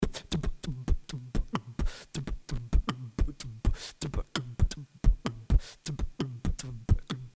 Сведение битбокса
Можно ли из обычной партии битбокса где в один канал вся ритм. партия ,нарулить звук как тут Я слышу что там семплы подмешаны, или это все-таки так битбокс обработан?